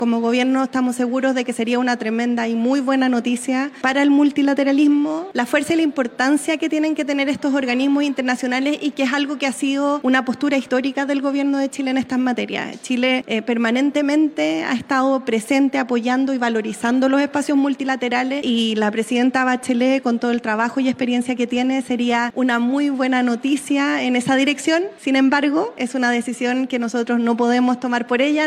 CUNA-VOCERA.mp3